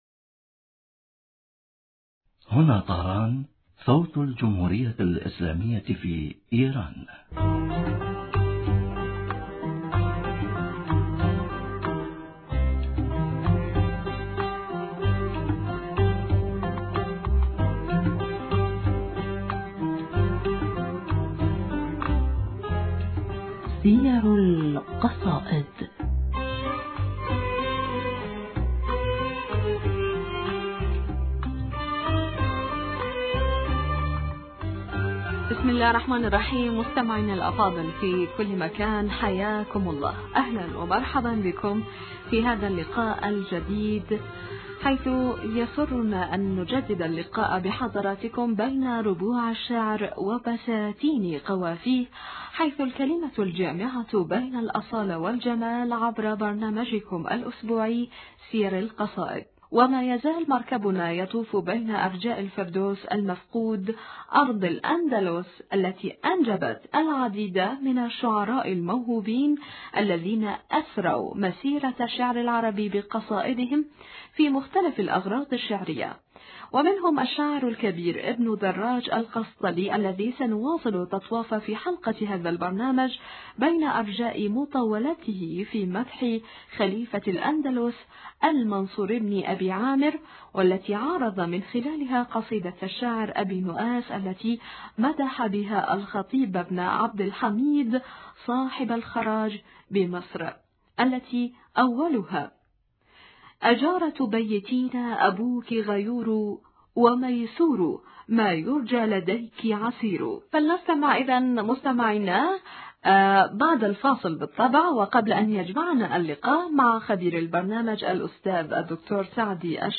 المحاورة